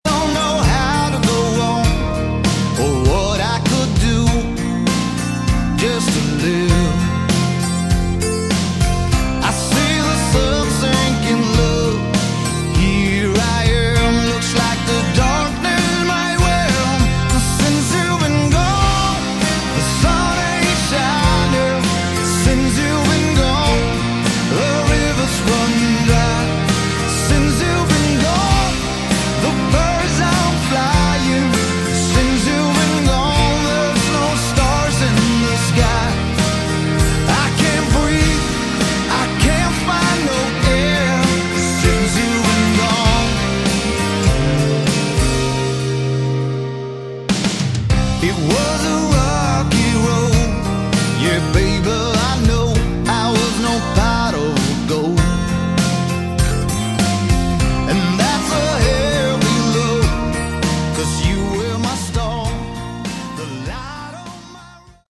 Category: Hard Rock
Guitar Solo